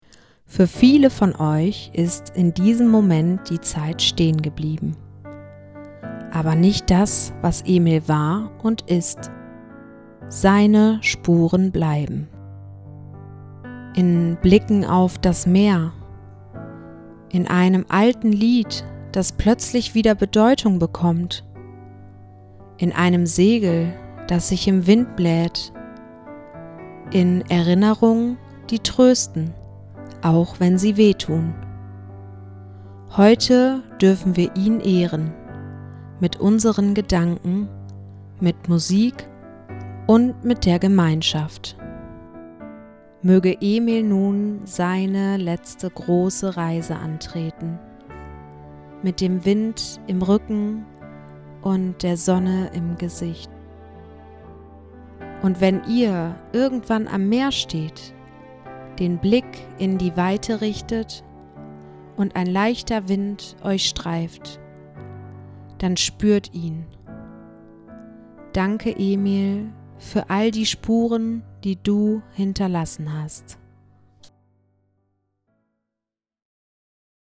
Die Trauerrede